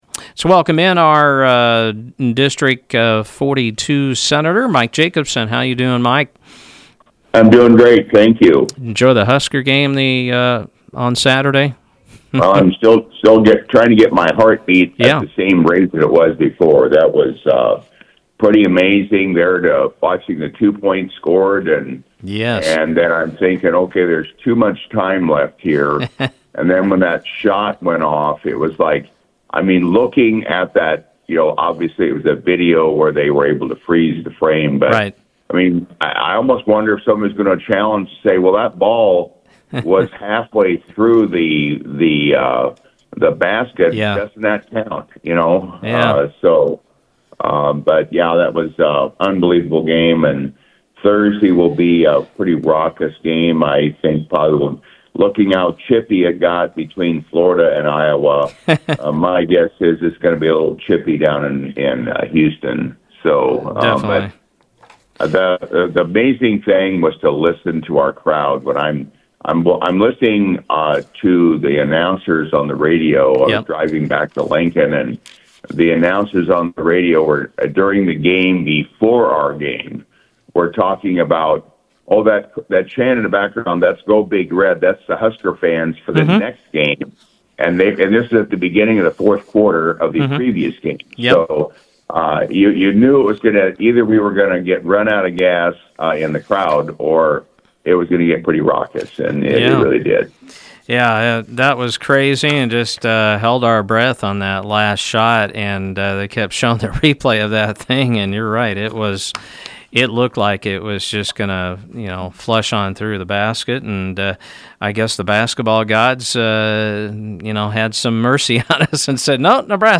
District 42 State Senator Mike Jacobson came on Mugs Monday to give updates on the budget bills and LB 1187 and LB 1261: